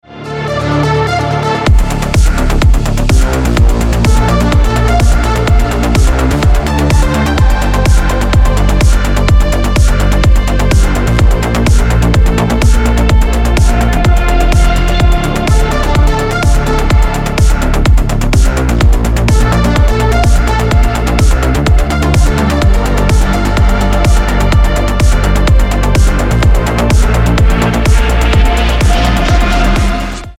• Качество: 320, Stereo
Electronic
без слов
progressive trance
динамичные
Крутой атмосферный progressive trance